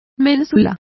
Complete with pronunciation of the translation of corbel.